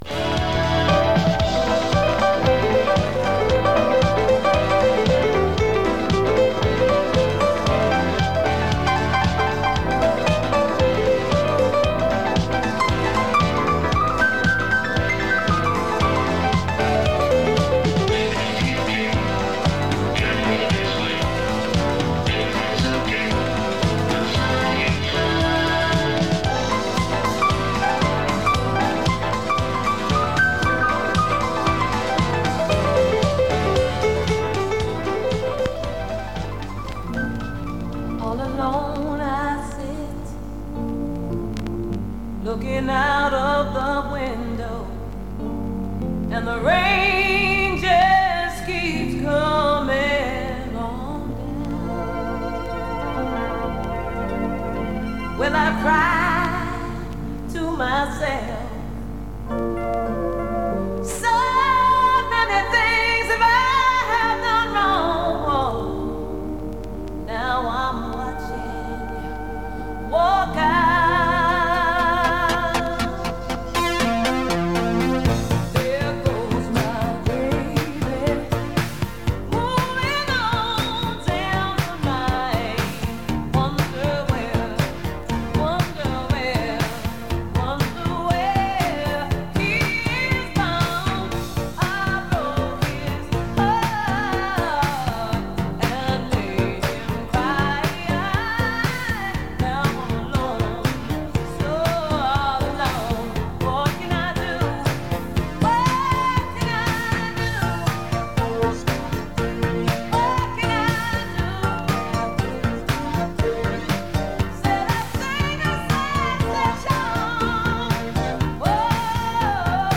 This is another recording of the early days of KISS FM from Foley Street in Dublin’s north inner city (1985-86).